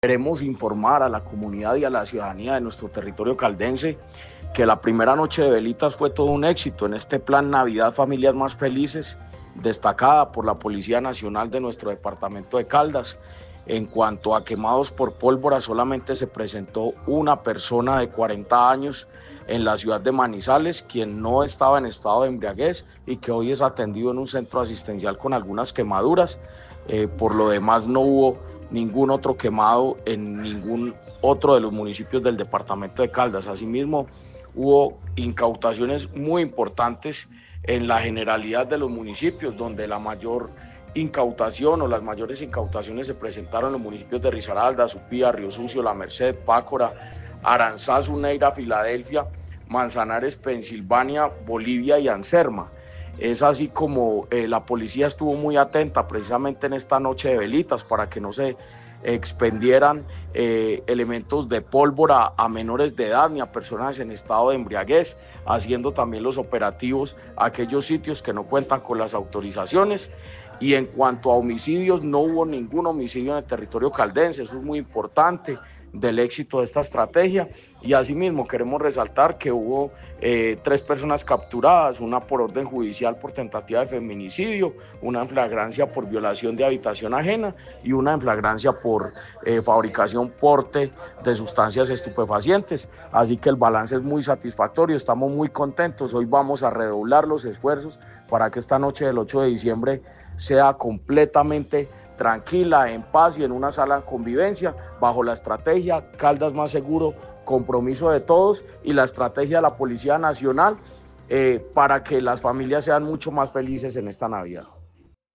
Jorge Andrés Gómez Escudero, secretario de Gobierno de Caldas